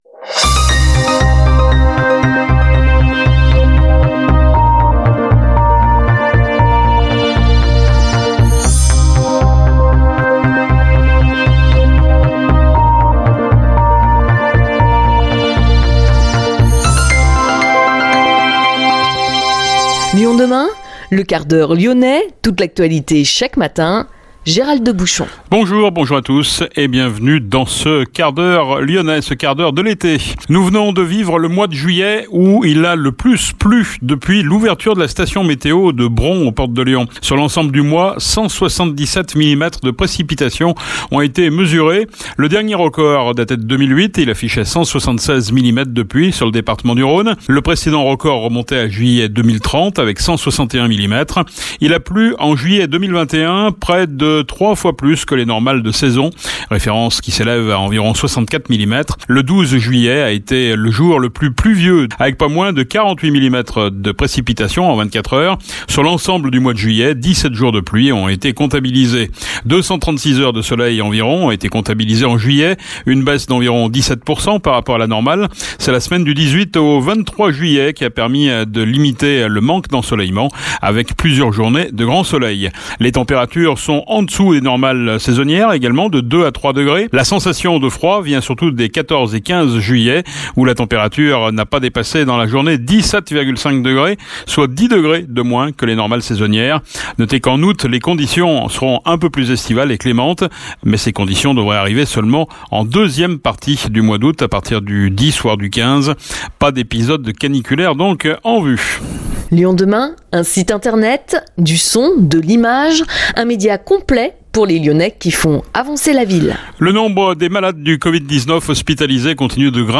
Le Quart-d’Heure Lyonnais est aussi diffusé en FM sur Pluriel 91,5, Salam 91,1 et Arménie 102,6